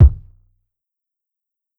Nemo Kick.wav